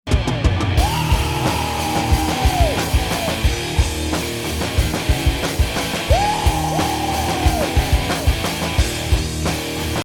avec compression=>
j'avoue que j'ai forcé un peu trop sur le dosage...; dans ce cas 70% de son compressé pour 30% de son d'origine (on peut faire bien mieux en dosant plus finement...
enfin le but était juste de montrer l'effet (dans ce cas fair ressortir la ride et les cymbales sans trop les rendre agressives!!)
on entend bien le côté "in the face" du à une compression vraiment très forte; et la chose est évidemment totalement adaptable pour le live (faut juste faire attention au larsen car les prises aussi fortement compressé font partir le micro rapidement en vrille...